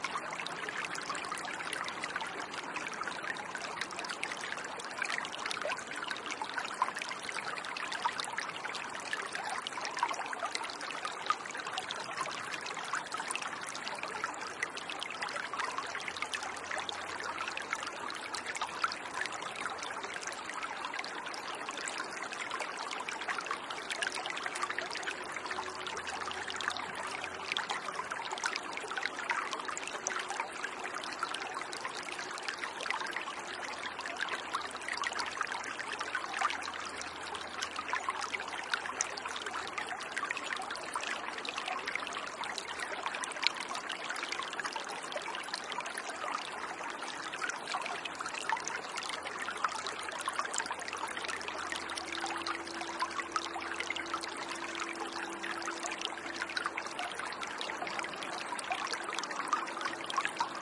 描述：西班牙Aracena附近一条小溪的不同视角。麦克风设置在水流中间，有一个小三角架。M/S立体声
Tag: 场记录 性质 飞溅 冬天